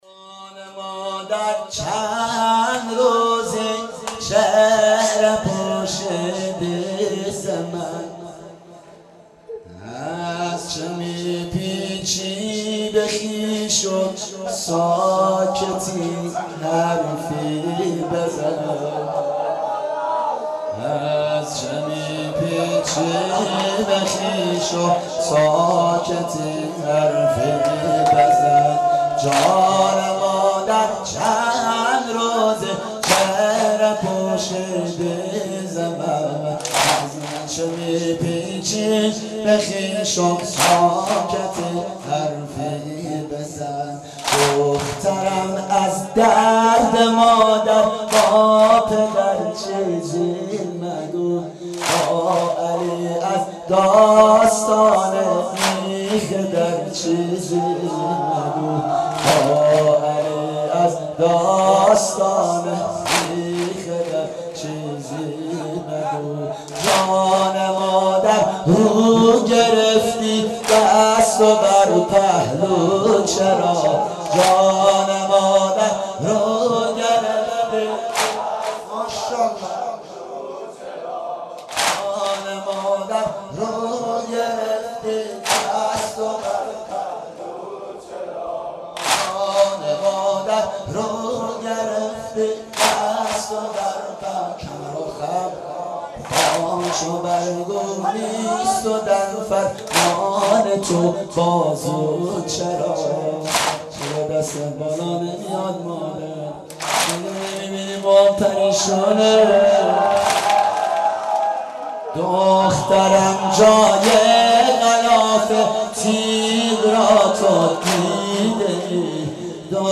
دانلود مداحی مادرم بیماره - دانلود ریمیکس و آهنگ جدید